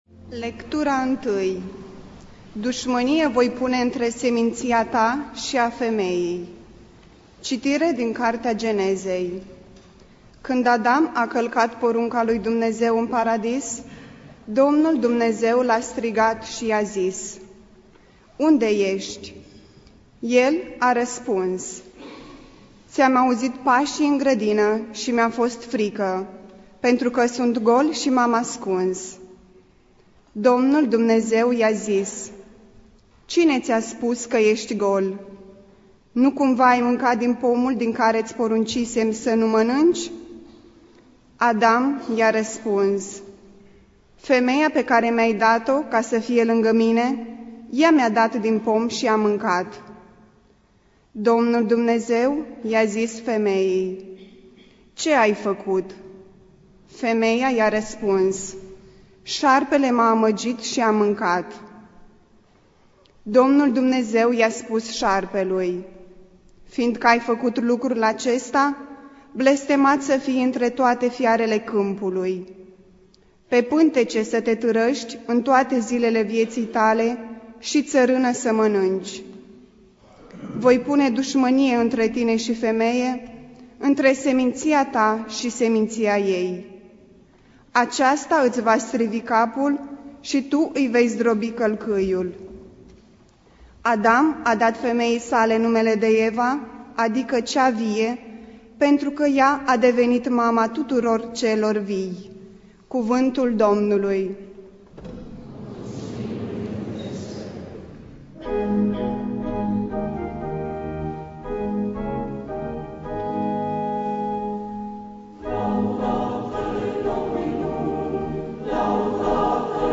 Biblioteca - Predici la Radio Iasi